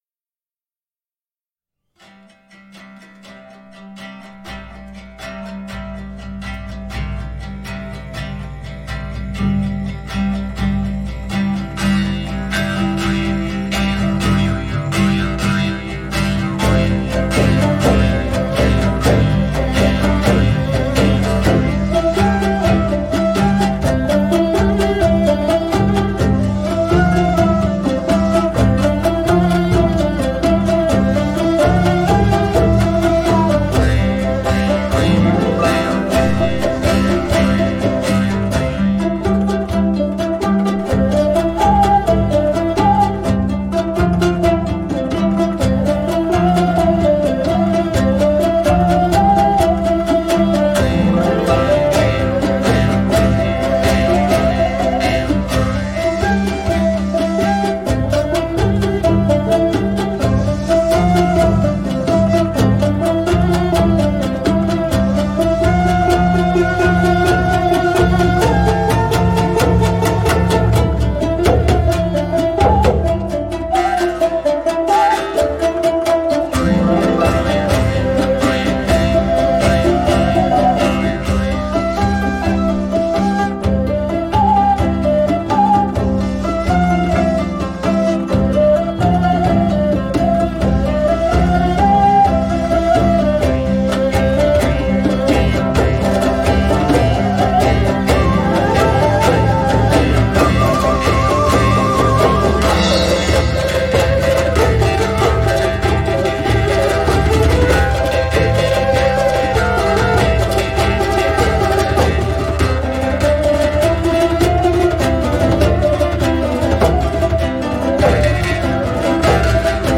ұлт аспаптар ансамбльдеріне арналған шығармалары